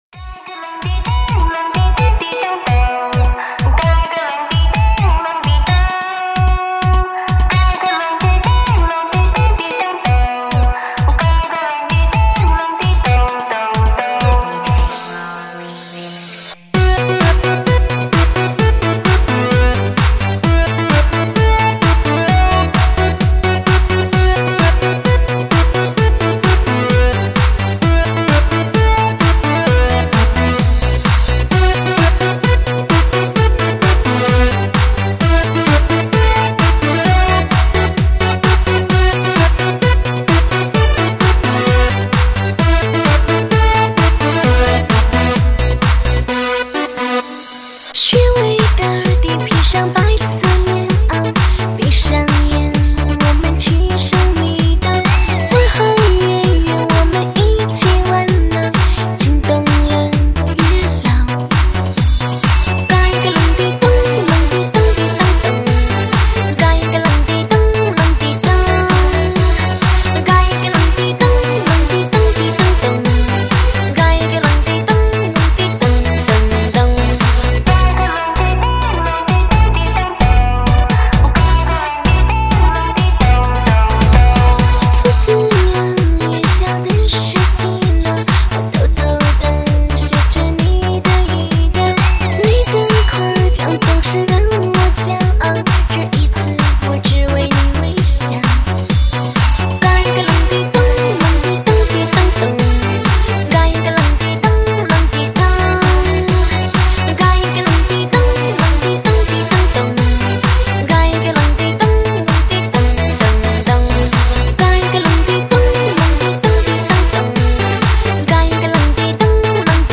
迪厅前卫潮流劲爆舞曲